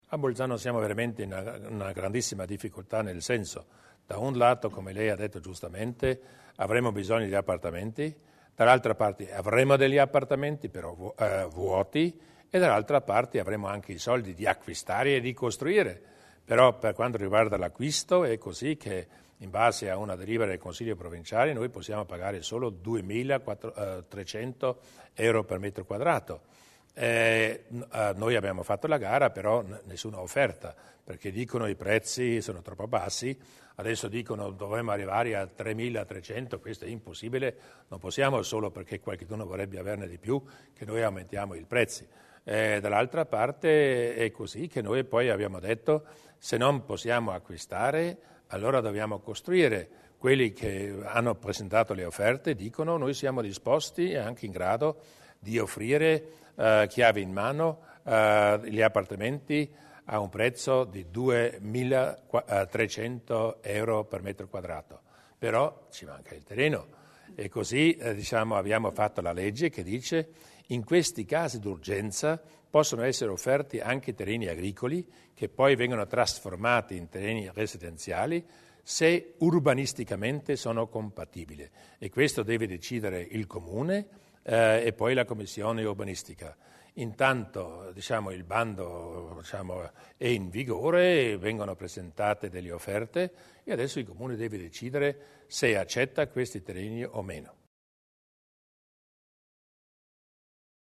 Il Presidente Durnwalder sulla soluzione al problema casa a Bolzano